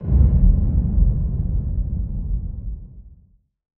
VEC3 FX Reverbkicks 02.wav